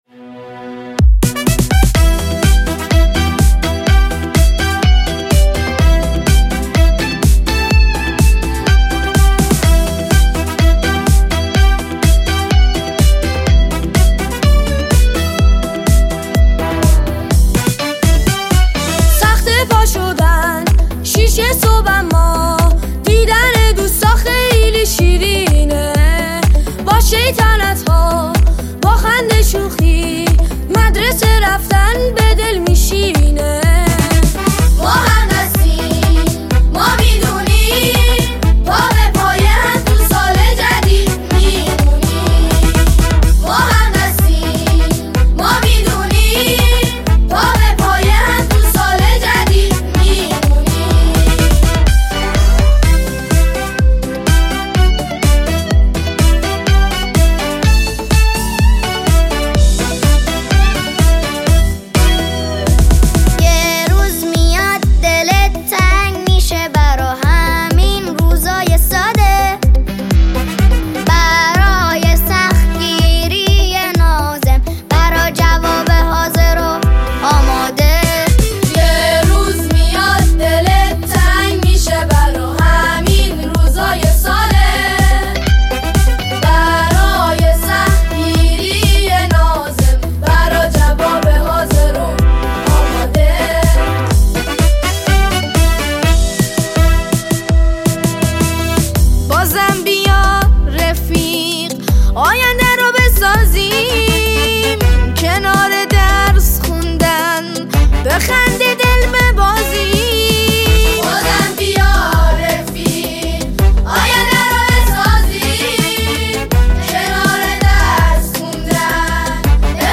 نماهنگ شاد، مهربان و پرانرژی
ژانر: سرود